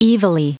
Prononciation du mot evilly en anglais (fichier audio)
Prononciation du mot : evilly